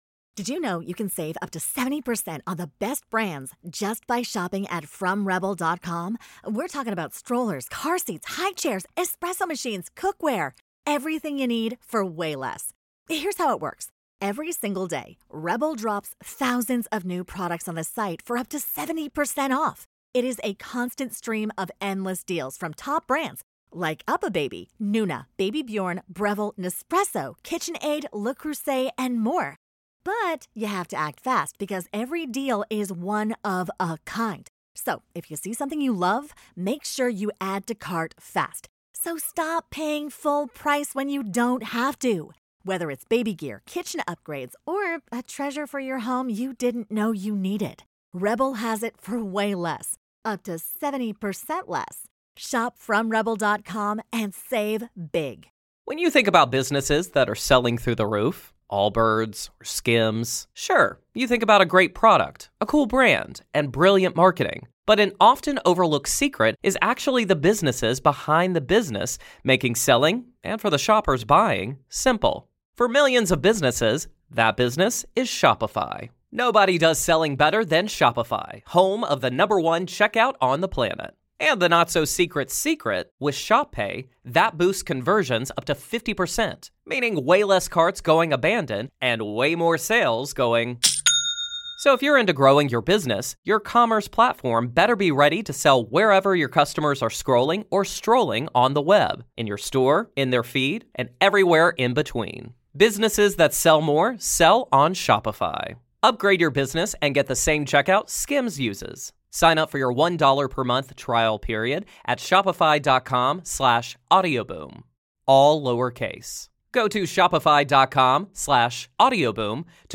This is more than a story of hauntings—it’s the story of a seeker who refused to look away from the unknown. This is Part Two of our conversation.